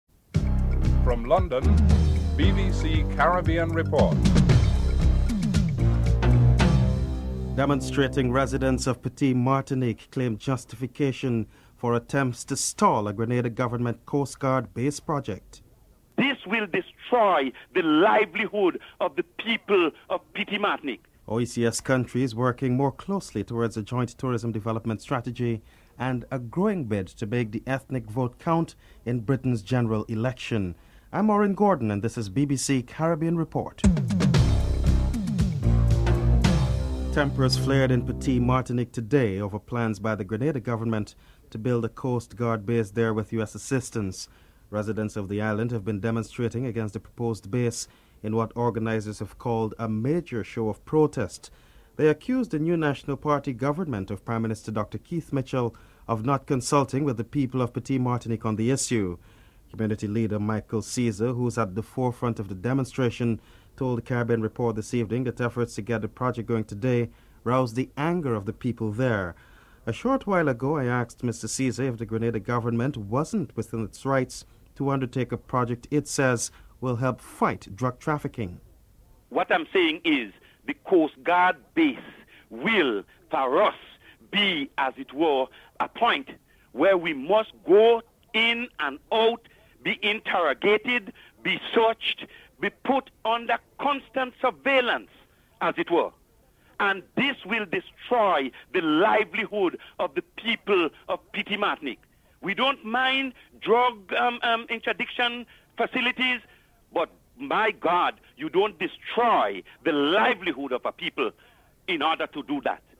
1. Headlines (00:00-00:36)
Roberto Robaina, Cuba's Foreign Minister is interviewed (08:30-09:06)